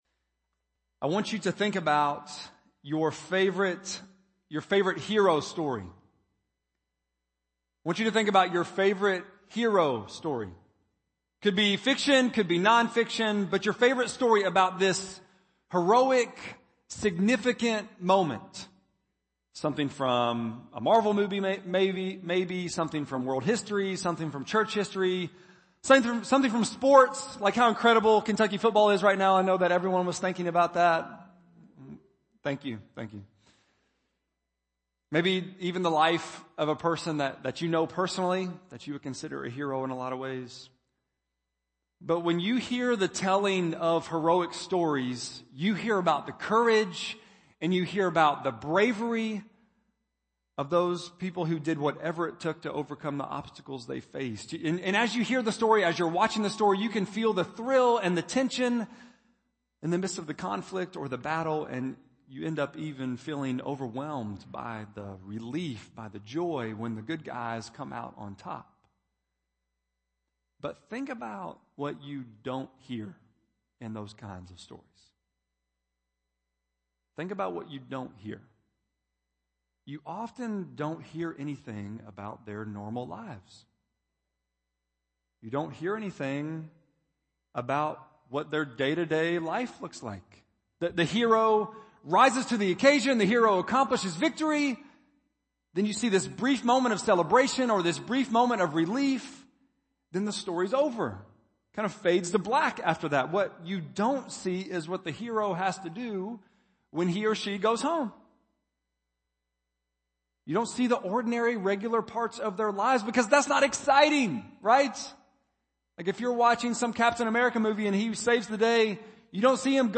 10.10-sermon.mp3